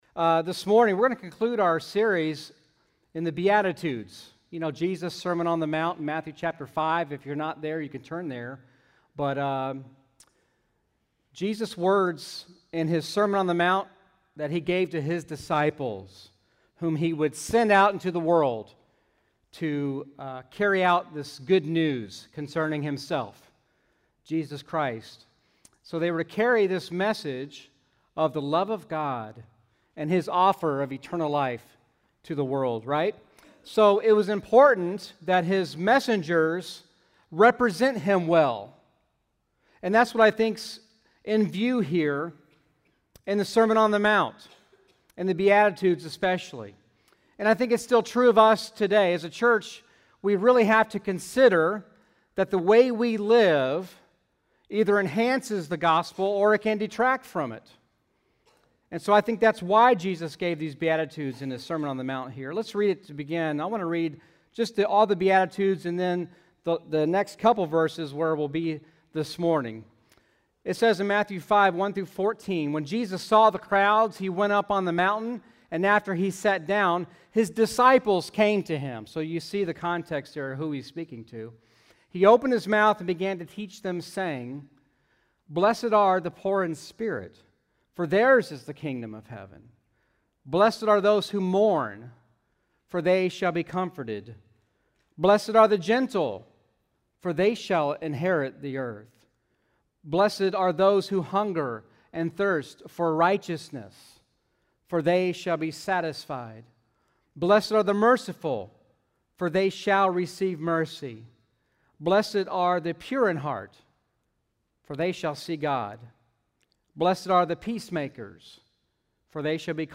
In our final sermon on the Beatitudes, we will explore Jesus’ words that follow the Beatitudes. You could say this represents the purpose of the Beatitudes: to live in such a way that the world sees our “beatitude attitudes” and glorifies our Father in Heaven.